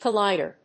/kʌˈlaɪdɝ(米国英語), kʌˈlaɪdɜ:(英国英語)/